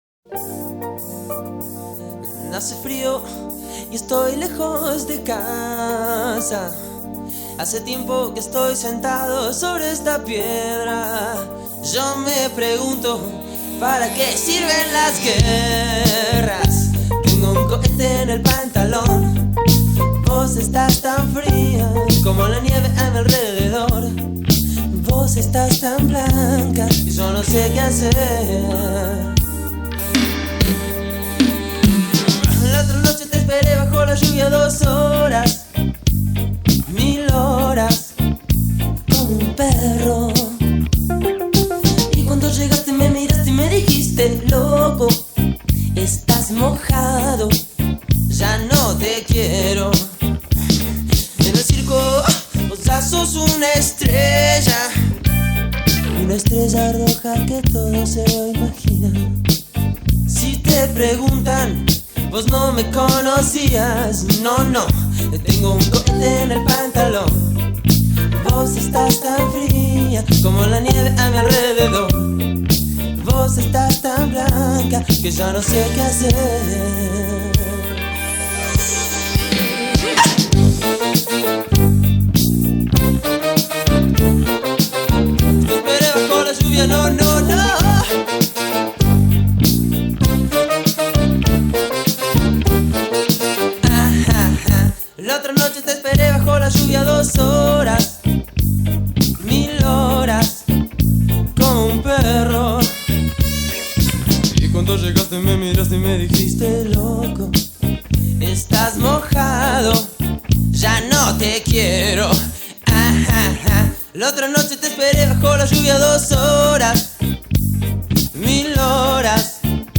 Carpeta: Rock argentino mp3